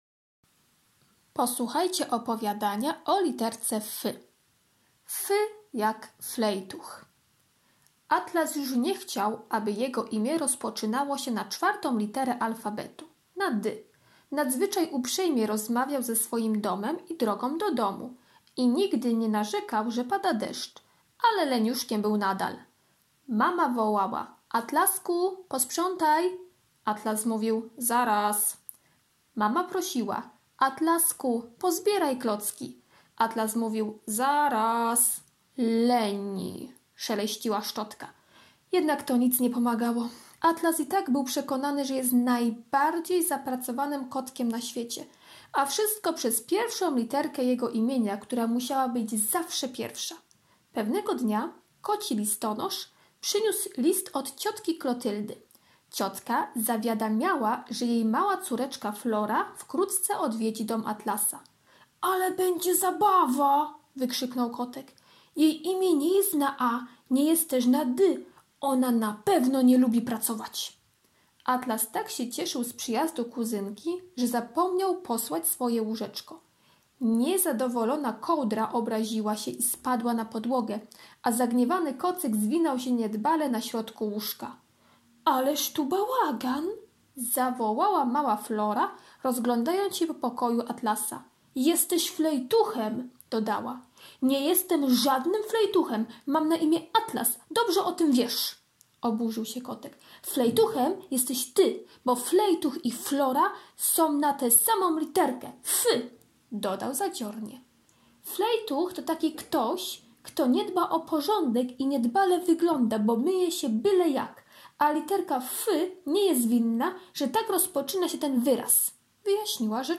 poniedziałek - opowiadanie "F jak flejtuch" [5.82 MB] poniedziałek - karta pracy nr 1 [168.30 kB] poniedziałek - ćw. dla chętnych - kolorowanka z literą F [480.03 kB] wtorek - tekst piosenki i wierszyka [26.50 kB]